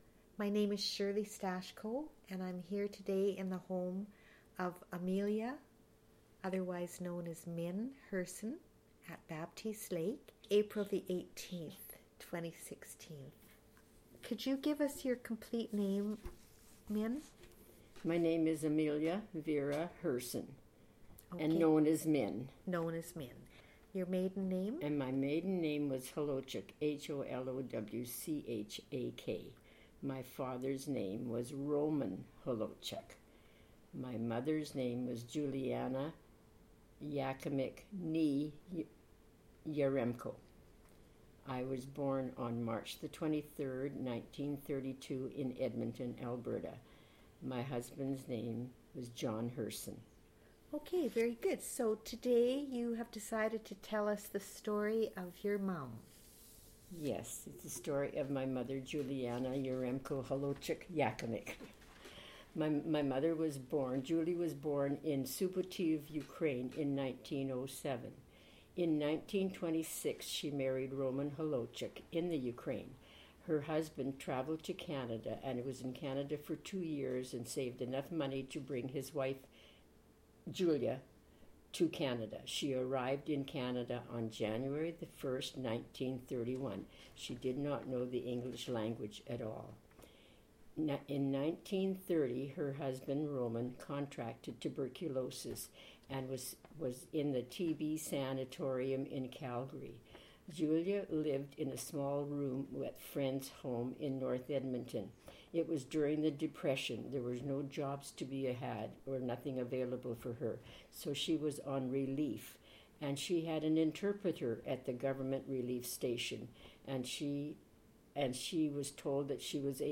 Audio interview and transcript of audio interview,